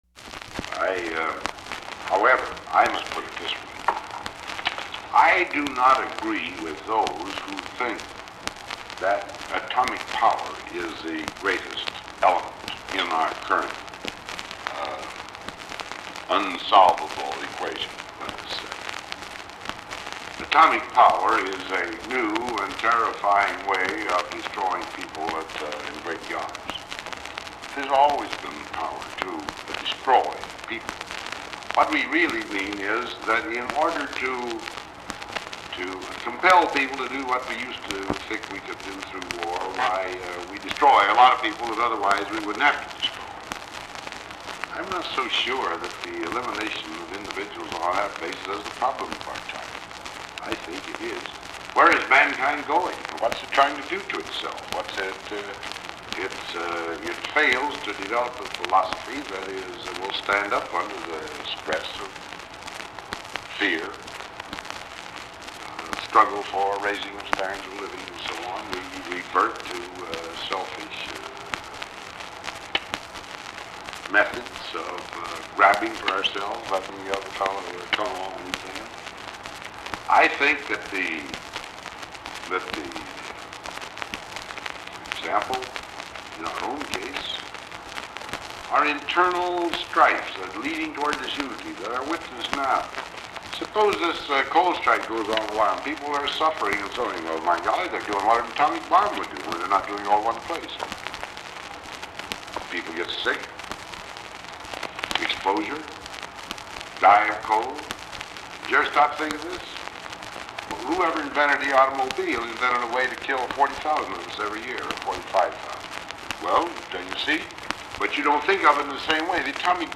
Secret White House Tapes | Dwight D. Eisenhower Presidency